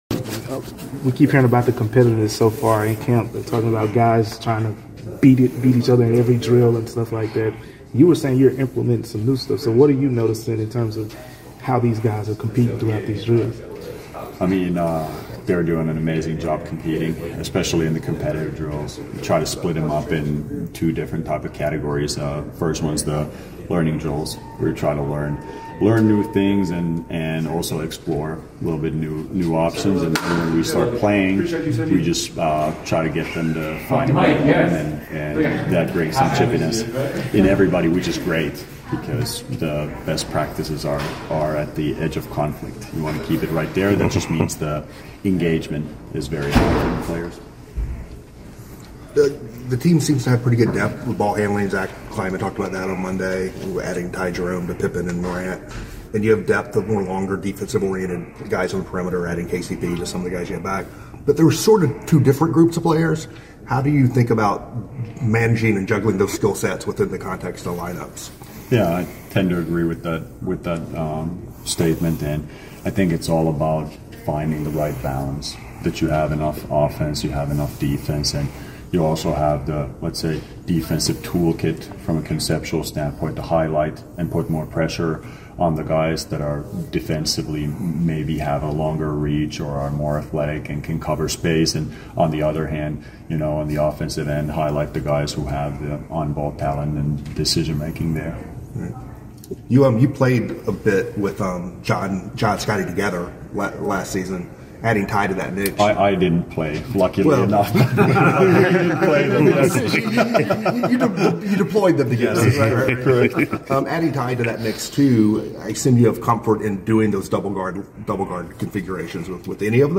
Memphis Grizzlies Coach Tuomas Iisalo Press Conference after the second day of Training Camp.